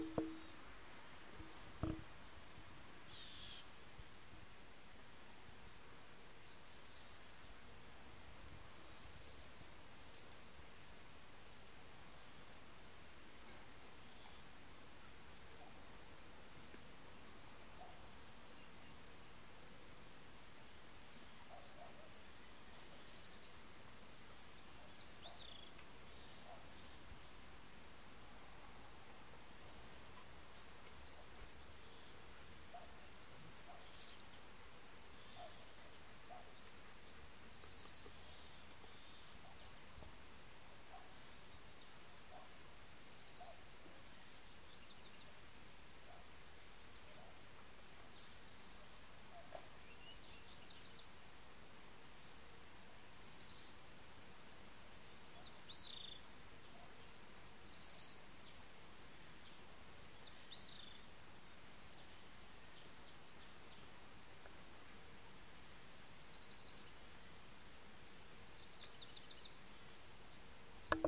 描述：鸟儿在花园里，一辆车在远处路过
Tag: 花园 汽车